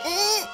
Worms speechbanks
ow3.wav